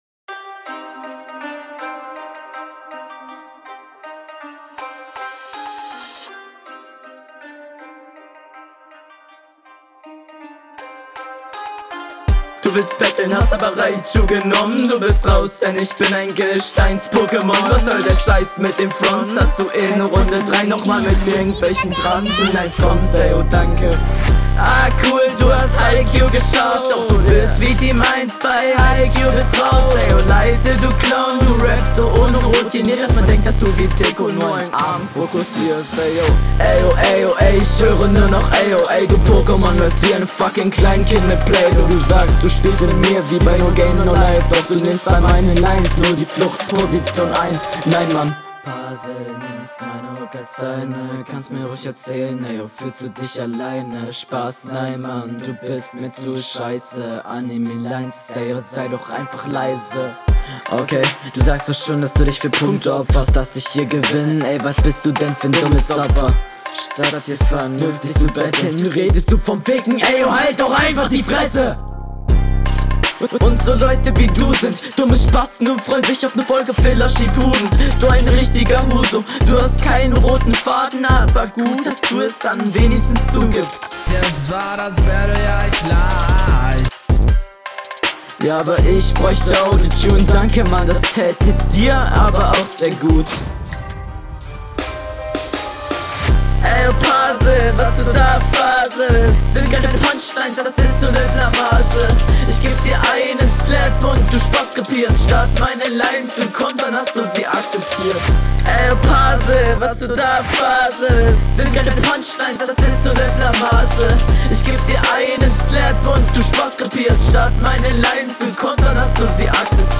Next Level Flow und die Lines waren grundsätzlich alle recht gut.